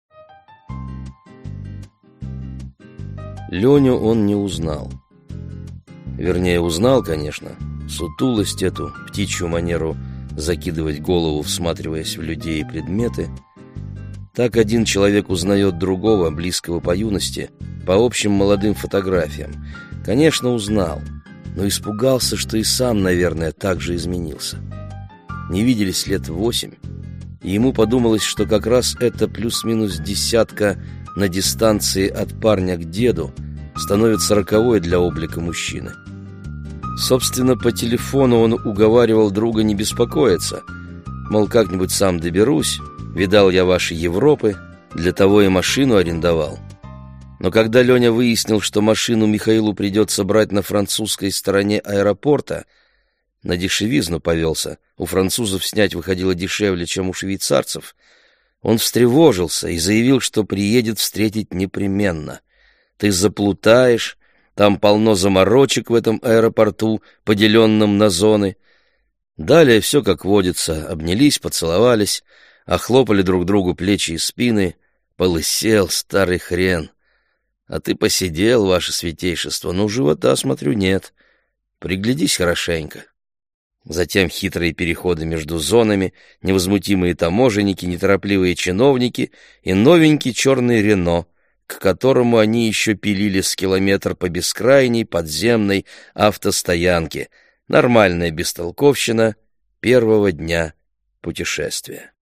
Аудиокнига В Сан-Серге туман…